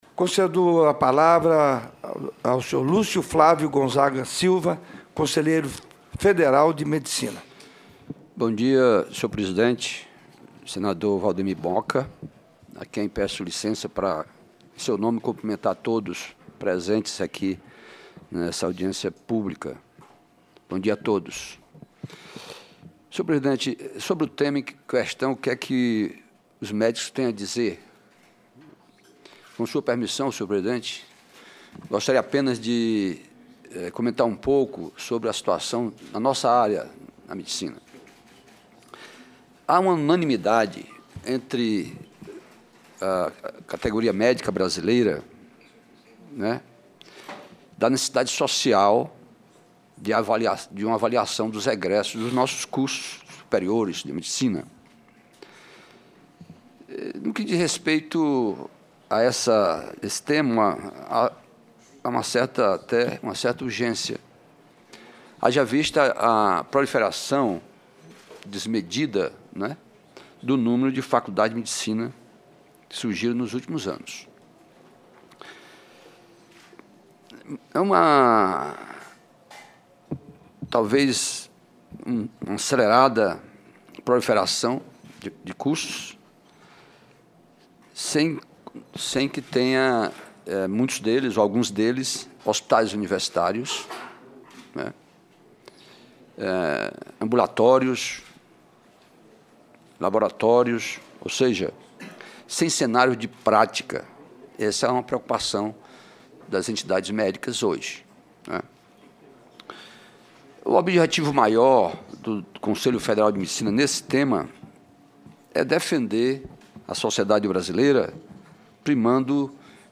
A Comissão de Assuntos Sociais debate, em audiência pública, na manhã desta quarta-feira, o exame promovido OAB, necessário para a obtenção do registro profissional. O teste é aplicado aos recém-formados em direito que pretendem exercer a profissão de advogados, mas algumas entidades são contrárias à exigência.